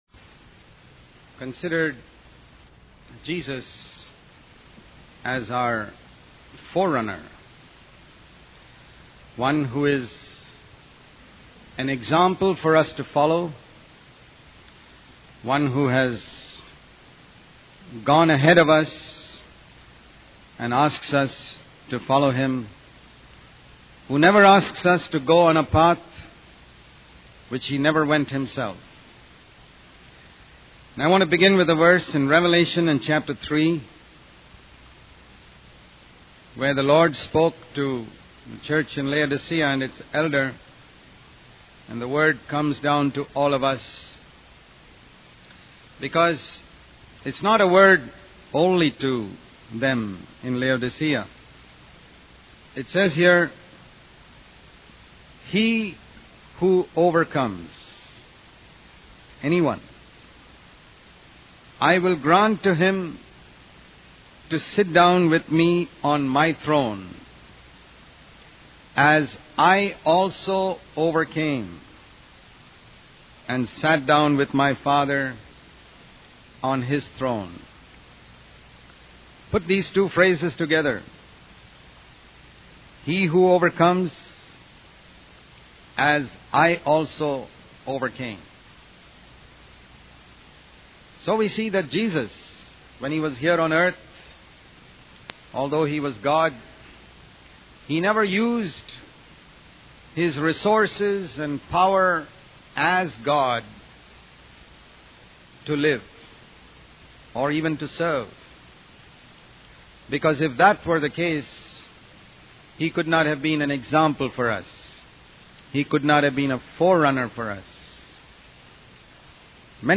In this sermon, the speaker expresses concern for young people who may start out well in their faith but fall away. He encourages them to follow his advice and not be influenced by negative examples in the Christian community.